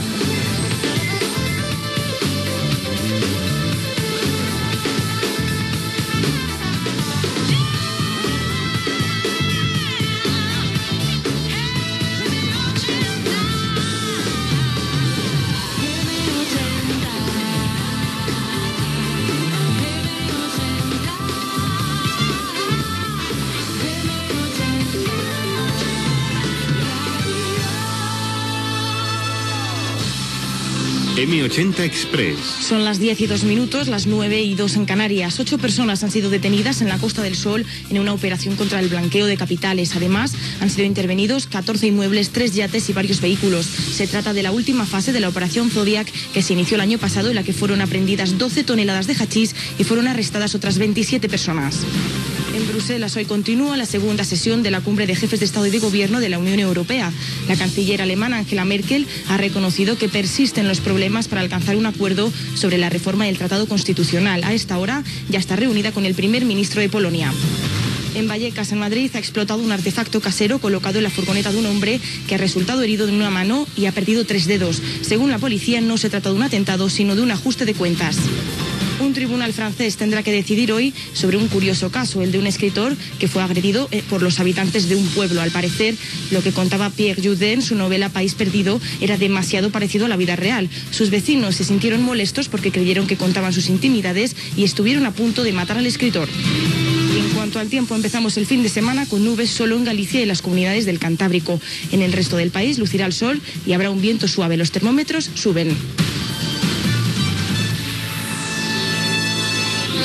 Careta del programa, resum de notícies i el temps
Informatiu